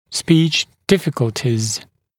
[spiːʧ ‘dɪfɪkəltɪz][спи:ч ‘дификэлтиз]проблемы с речью, нарушения речевой функции